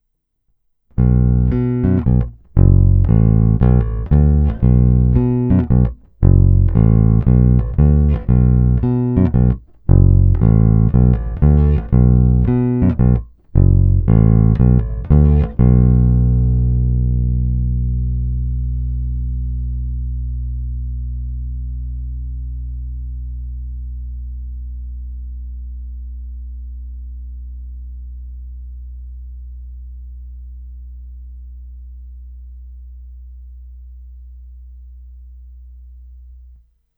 Zvuk je naprosto typický, a hlavně naprosto prvotřídní Jazz Bass, a to včetně skvěle artikulující struny H, na čemž se významnou měrou jistě podílí i prodloužená menzura.
Není-li uvedeno jinak, následující nahrávky jsou provedeny rovnou do zvukové karty a s plně otevřenou tónovou clonou. Nahrávky jsou jen normalizovány, jinak ponechány bez úprav.
Snímač u krku